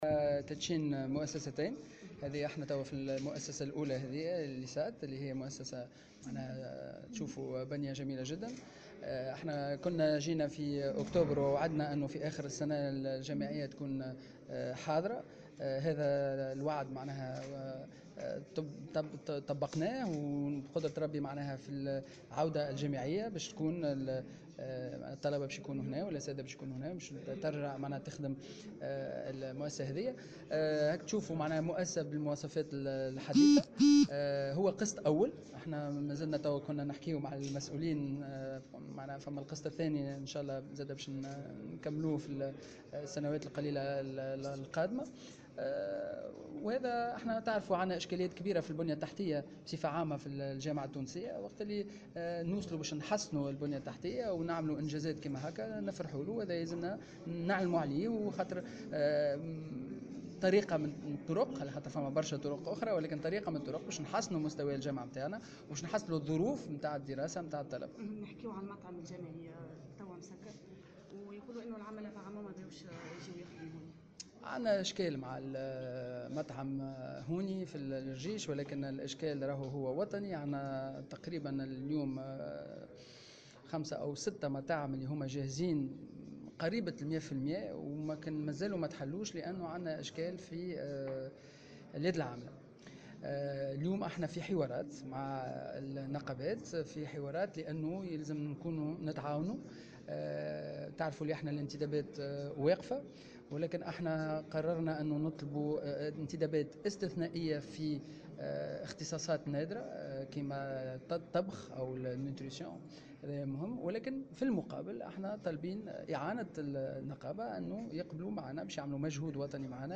وأكد الوزير في تصريحات صحفية على أهمية تحسين البنية التحتية للجامعات.